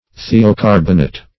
Thiocarbonate \Thi`o*car"bon*ate\, n.